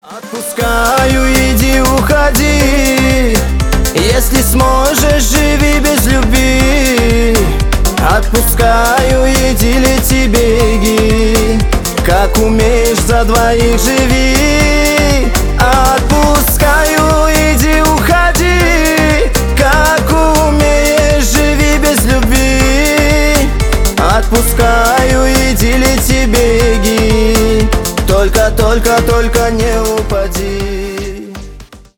Поп Музыка
кавказские